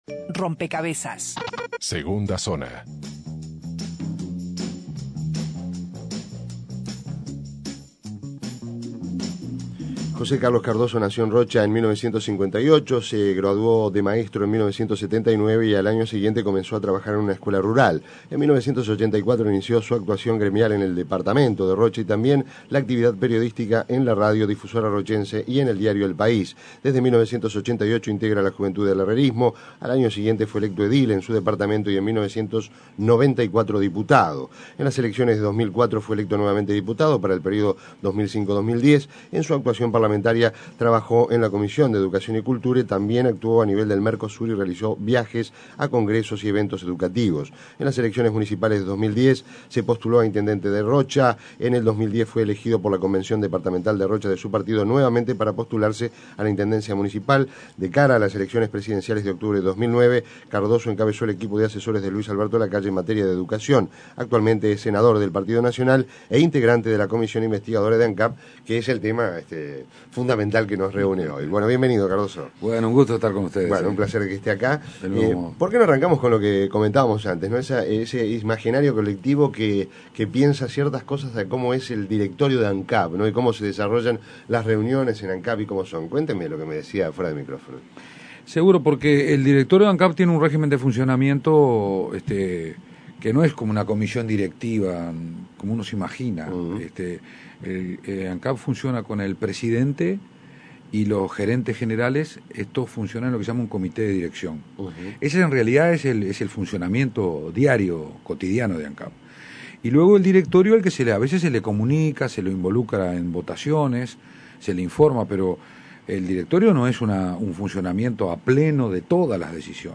Descargar Audio no soportado Entrevista a Jose Carlos Cardoso Ver video completo